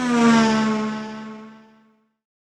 Roland.Juno.D _ Limited Edition _ GM2 SFX Kit _ 08.wav